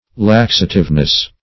Laxativeness \Lax"a*tive*ness\, n. The quality of being laxative.